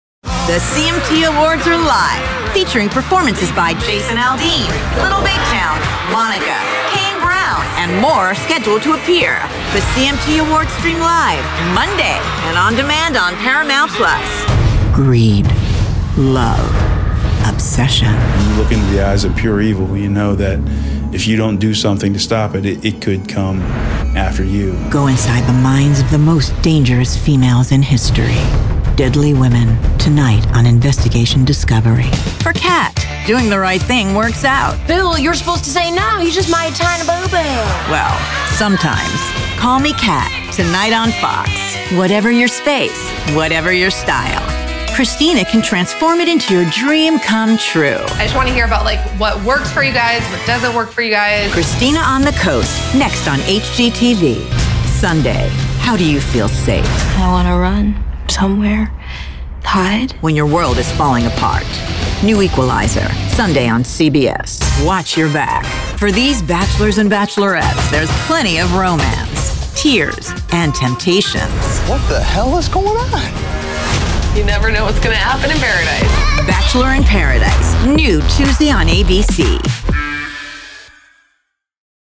Voiceover Artist,
Sex: Female
Ages Performed: Young Adult, Middle Age,
Sennheiser MKH 416 Microphone, Neumann TLM 103 Microphone, Audient ID14 Interface